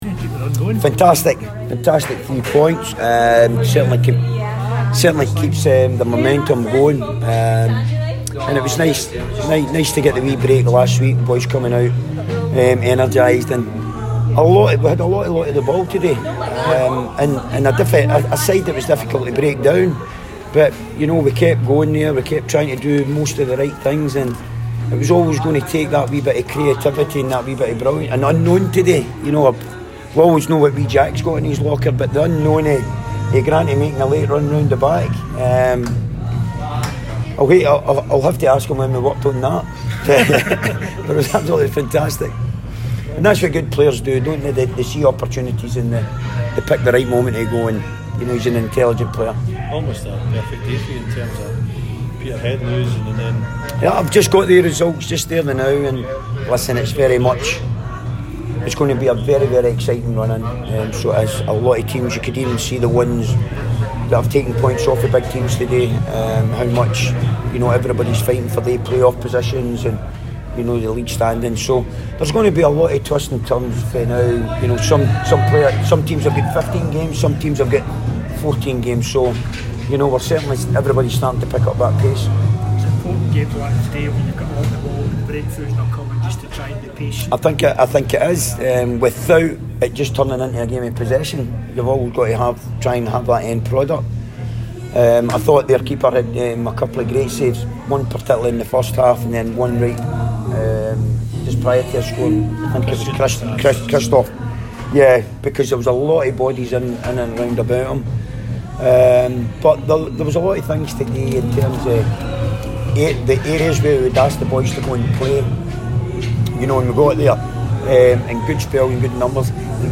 Danny Lennon's press conference after the Ladbrokes League 2 match.